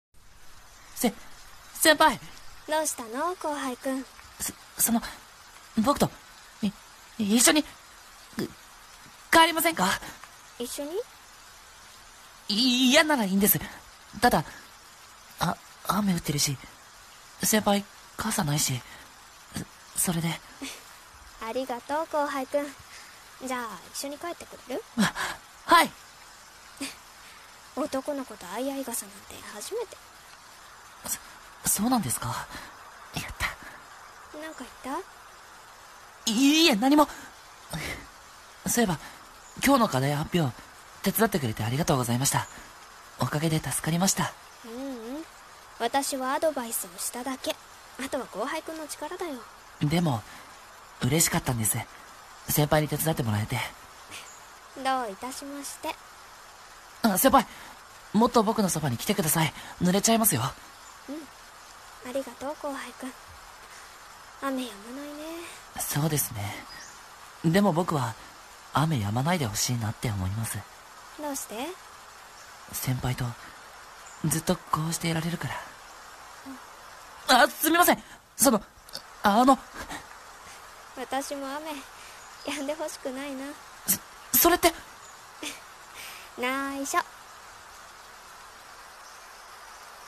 【声劇】先輩と後輩【掛け合い】